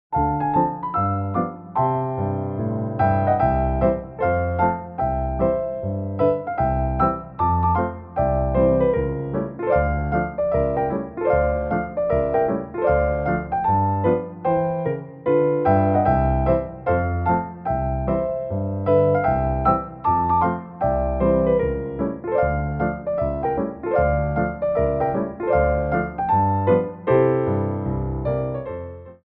Medium Allegro 2
4/4 (16x8)